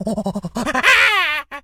monkey_chatter_angry_07.wav